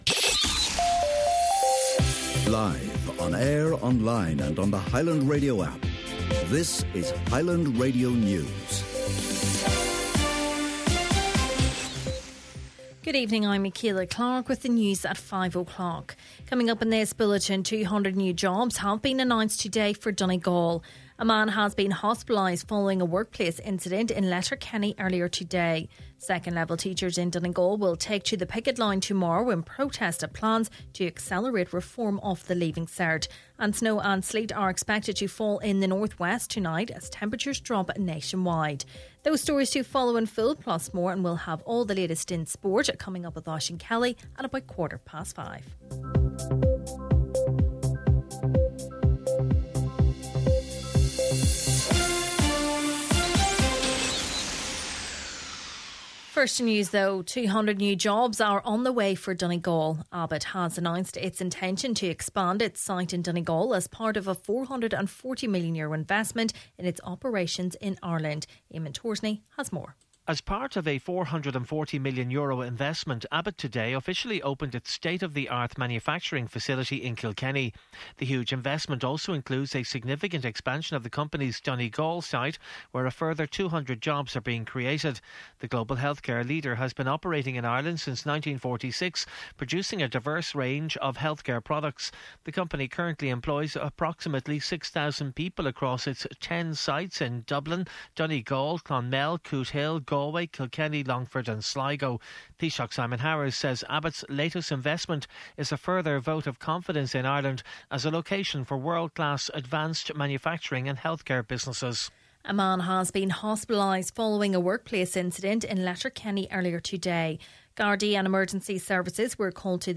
Main Evening News, Sport and Obituaries – Monday, November 18th